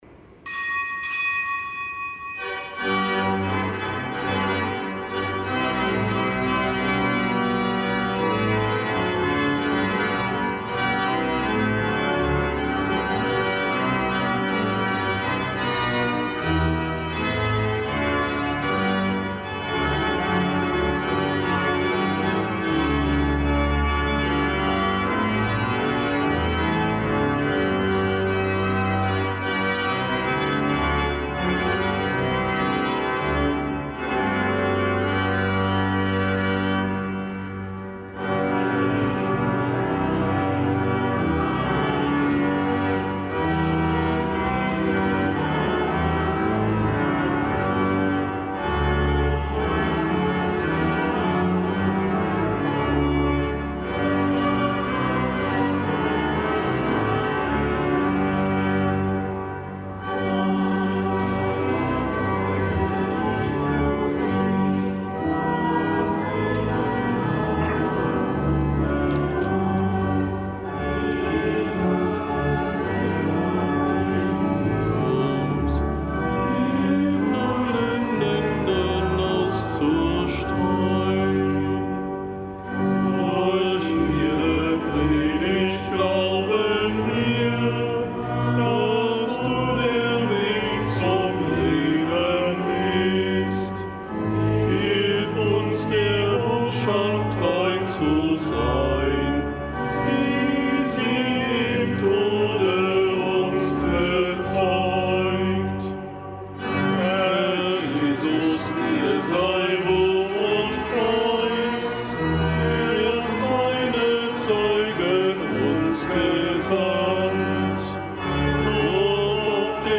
Kapitelsmesse aus dem Kölner Dom am Fest des Heiligen Apostels Andreas.